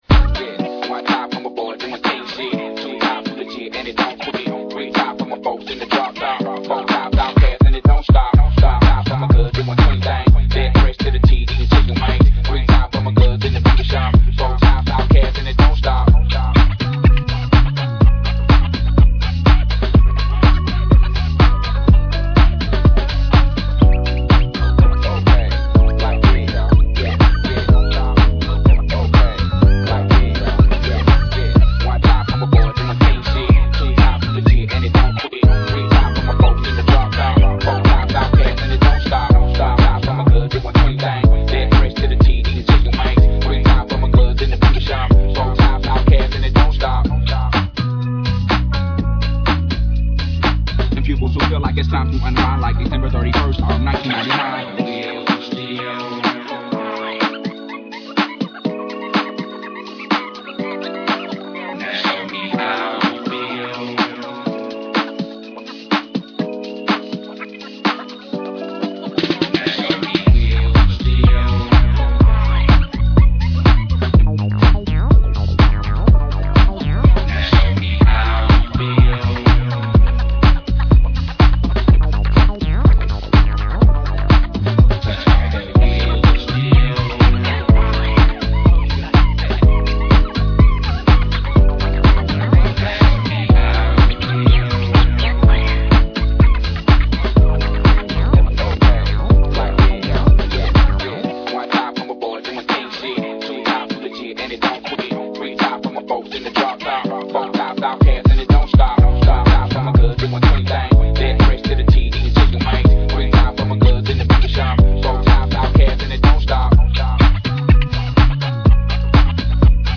Bringing back the old school rap & hip hop flava